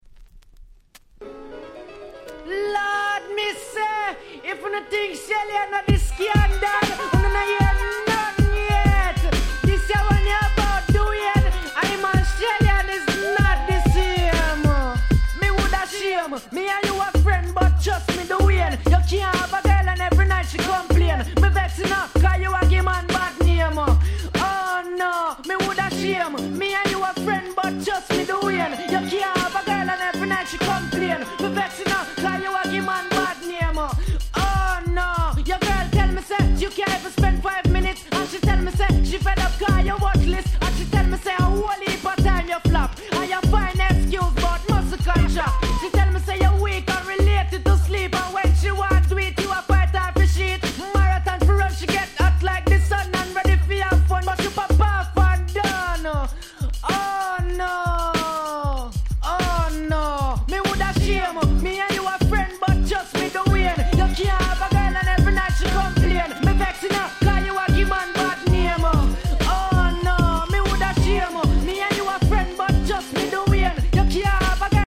問答無用のDancehall Classic !!